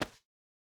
Grab Cloth High A.wav